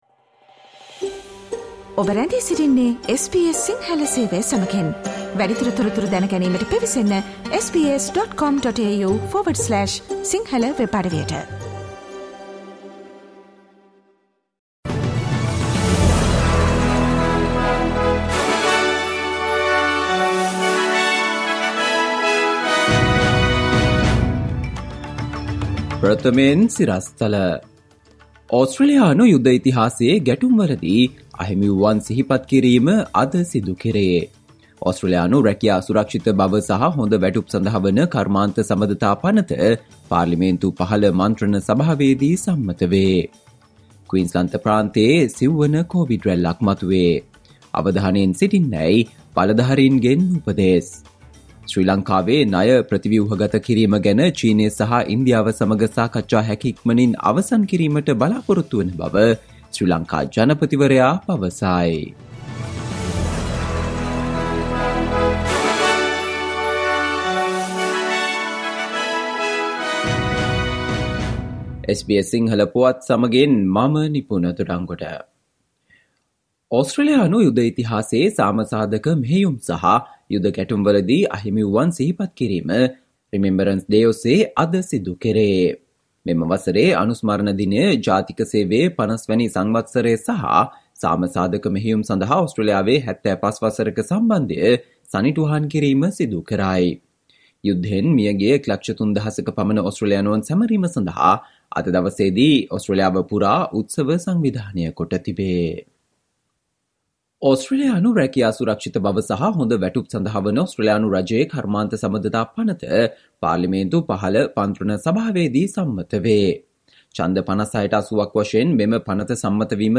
Listen to the SBS Sinhala Radio news bulletin on Friday 11 November 2022